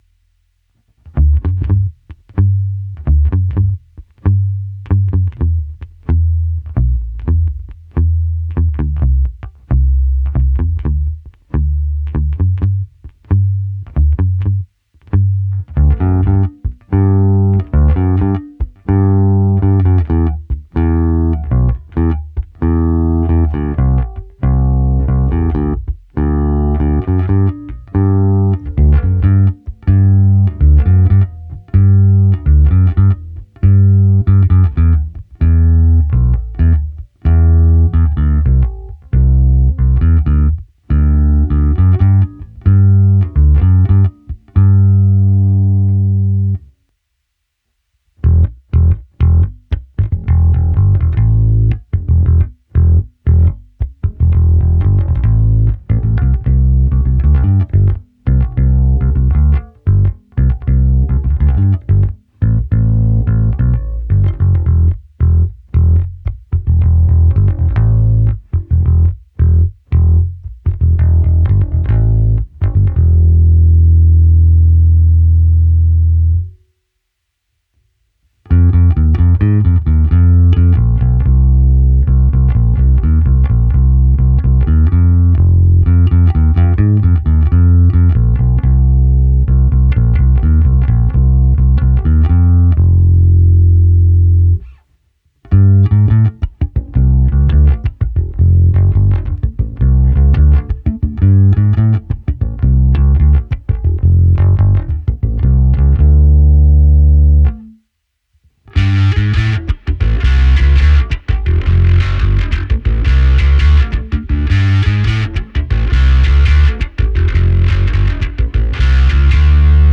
Zvuk je opravdu šedesátkový, středobasový, ne tak ostrý jako padesátkové nebo sedmdesátkové kousky, ale není ani zahuhlaný.
Hráno vždy s plně otevřenou tónovou clonou.
Nahrávka se simulací aparátu, hra trsátkem s tlumením, trsátkem bez tlumení a pak už jen prsty, nakonec zařazení zkreslení a trocha slapu.